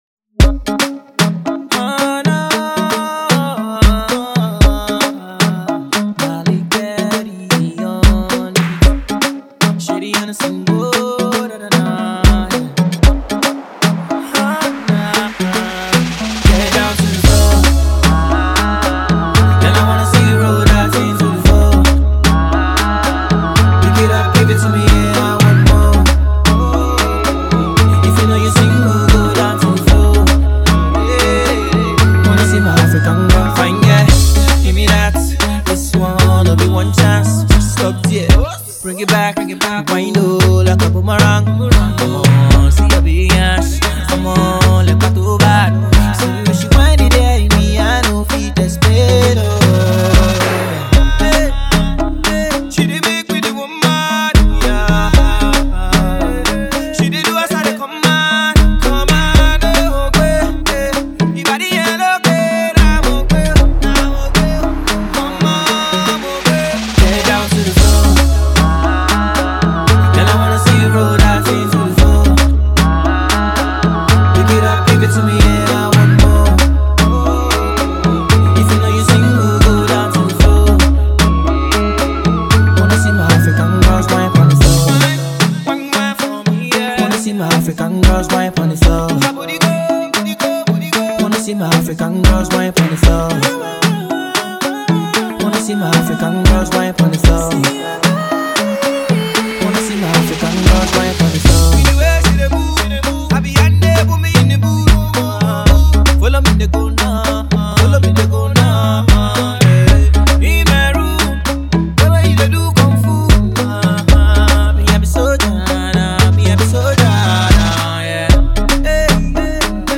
this song is guaranteed to fire up the dance floor.